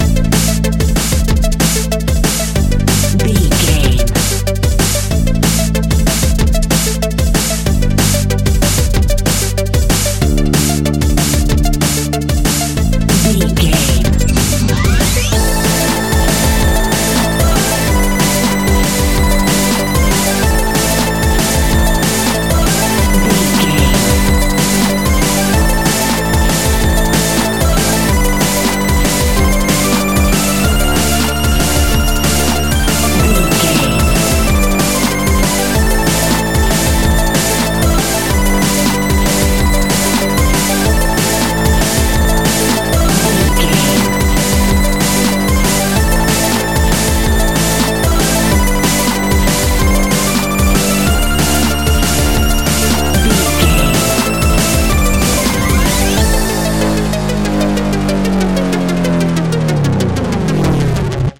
Epic / Action
Fast paced
Aeolian/Minor
intense
futuristic
energetic
driving
dark
aggressive
drum machine
electronic
sub bass
synth leads
synth bass